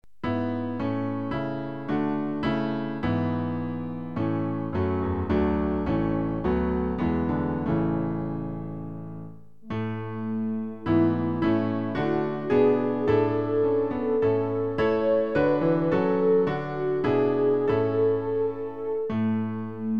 Klavier-Playback zur Begleitung der Gemeinde
MP3 Download (ohne Gesang)